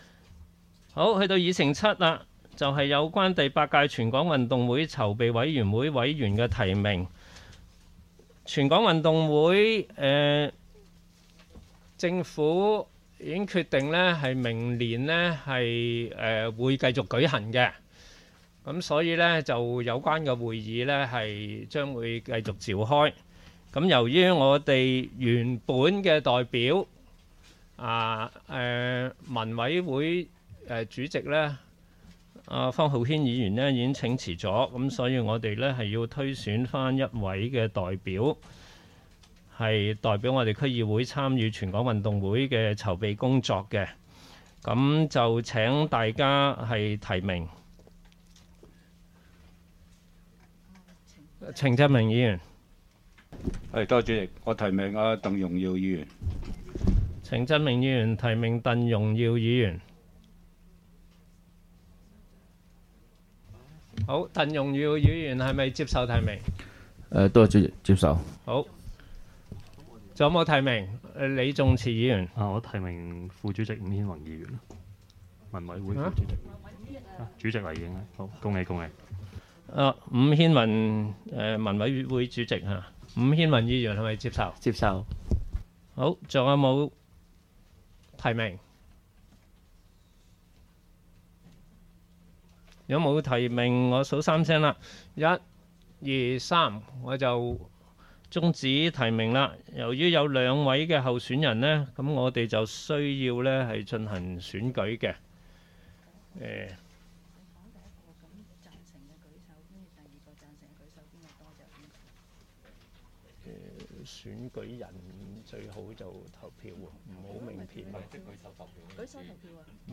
區議會大會的錄音記錄
地點: 元朗橋樂坊2號元朗政府合署十三樓會議廳